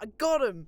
Voice Lines / Combat Dialogue